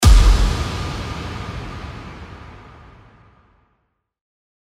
FX-733-IMPACT
FX-733-IMPACT.mp3